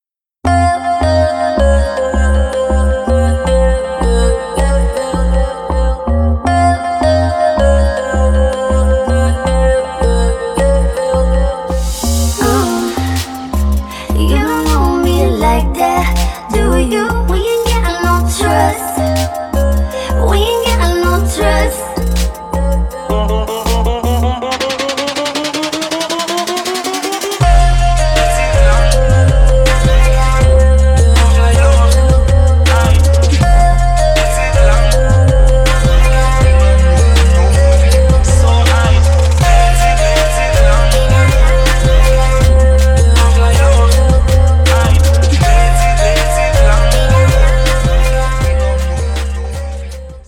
• Качество: 320, Stereo
dance
club